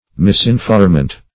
Misinformant \Mis`in*form"ant\
misinformant.mp3